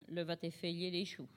Localisation Sallertaine
Collectif-Patois (atlas linguistique n°52)
Catégorie Locution